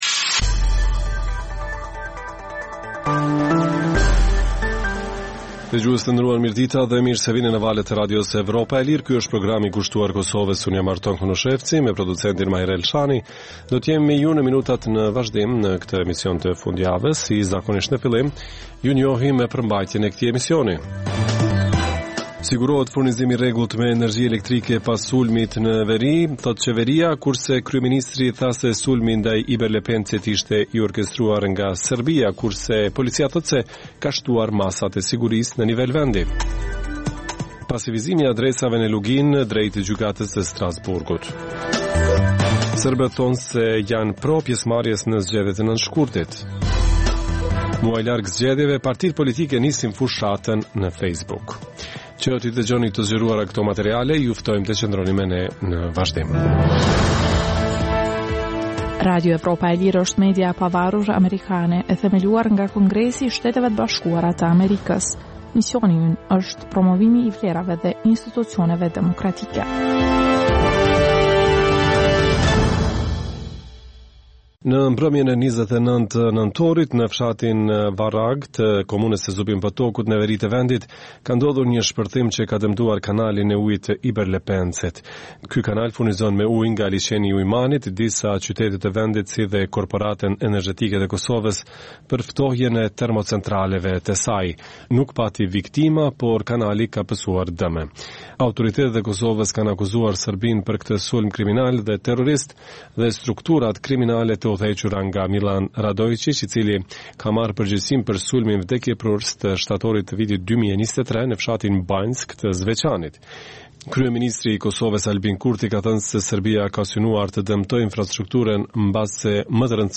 Emisioni i orës 16:00 është rrumbullakësim i zhvillimeve ditore në Kosovë, rajon dhe botë. Rëndom fillon me buletinin e lajmeve dhe vazhdon me kronikat për zhvillimet kryesore të ditës. Në këtë edicion sjellim edhe intervista me analistë vendës dhe ndërkombëtarë.